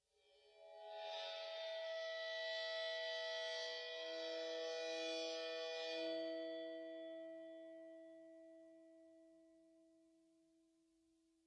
标签： MIDI-速度-63 单票据 多重采样 打击乐器 MIDI音符-103 VSCO-2 fsharp7
声道立体声